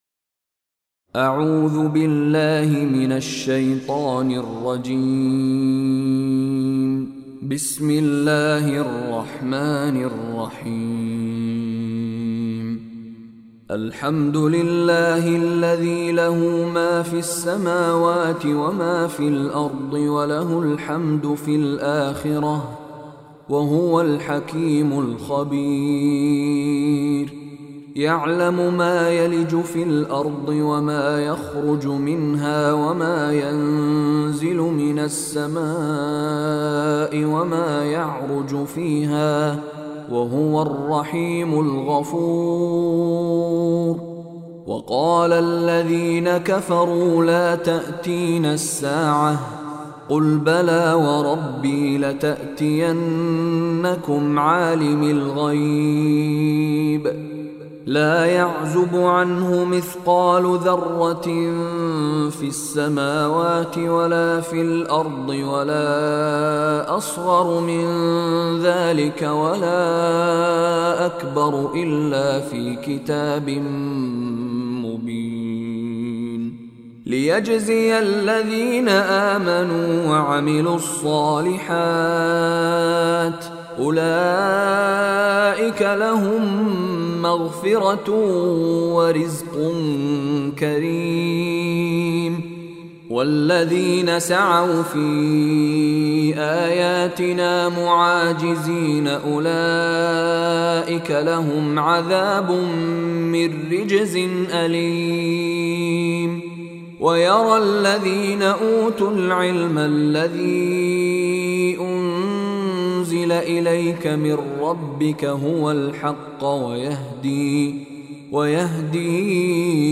Surah Saba beautiful Quran tilawat / recitation in the voice of Sheikh Mishary Rashid Alafasy.